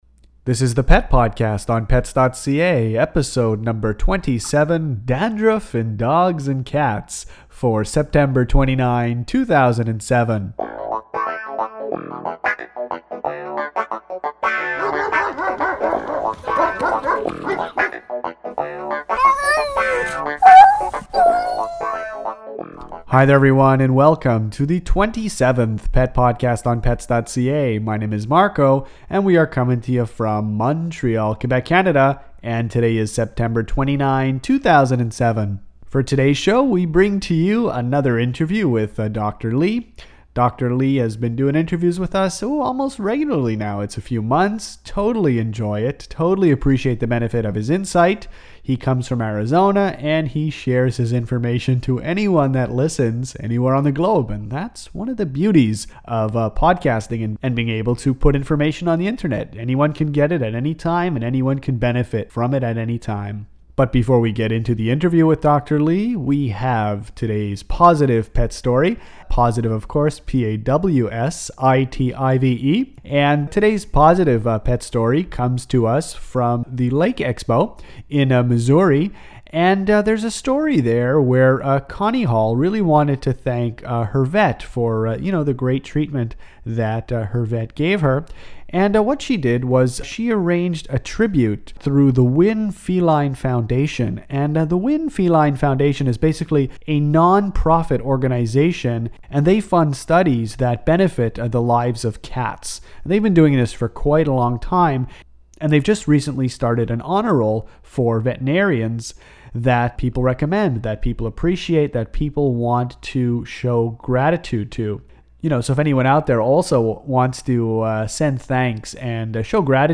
Dandruff in dogs and cats – Pet podcast #27 – Interview